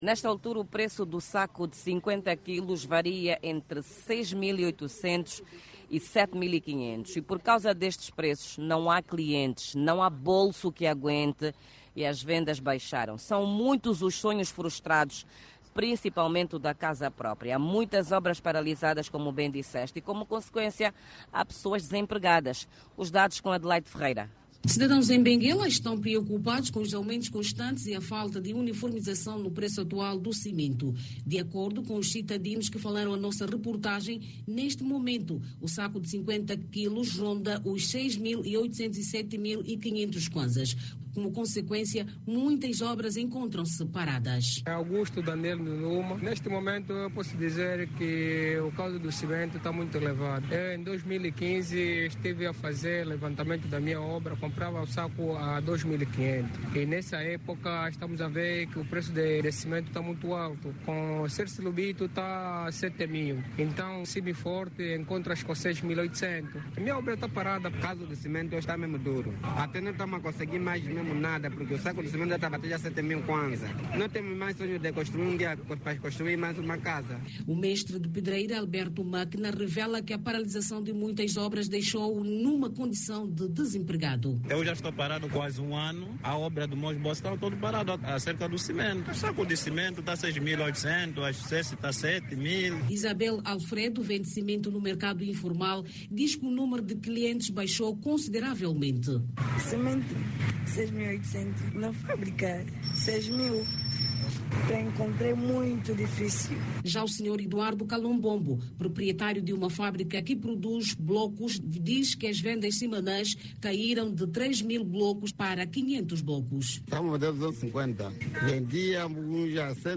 O aumento constante dos preços do cimento em Benguela preocupa a população daquela província. Nesta altura, a situação está a levar a paralisação de muitas obras e também muita gente ao desemprego. Clique no áudio abaixo e ouça a reportagem